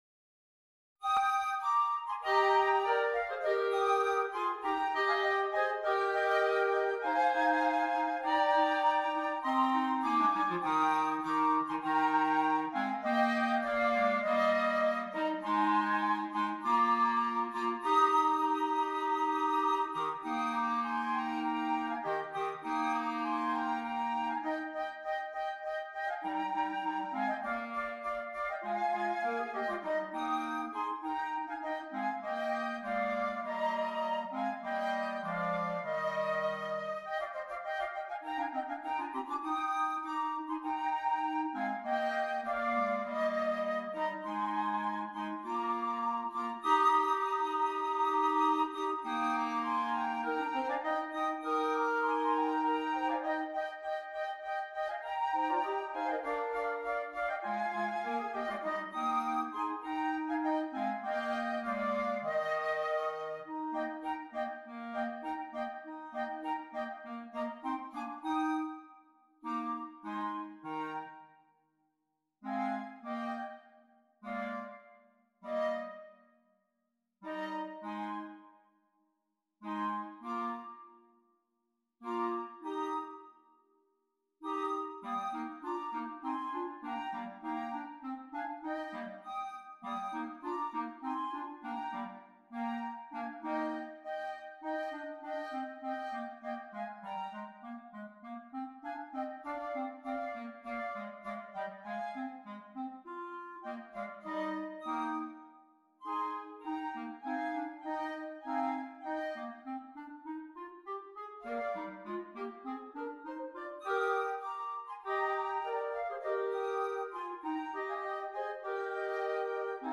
2 Flutes, 2 Clarinets
Traditional